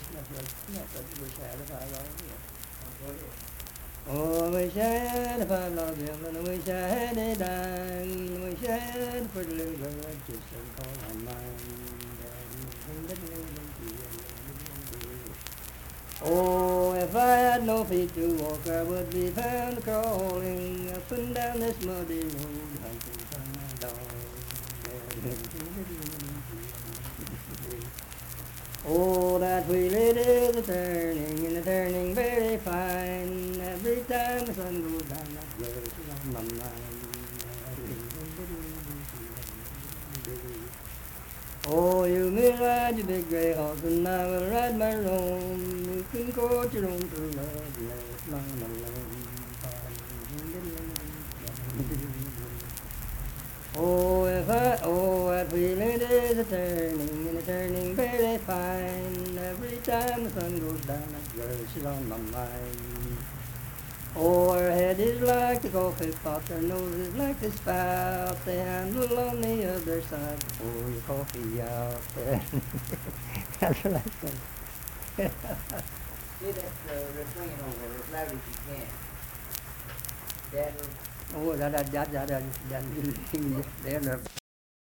Unaccompanied vocal music
Dance, Game, and Party Songs
Voice (sung)
Lincoln County (W. Va.), Harts (W. Va.)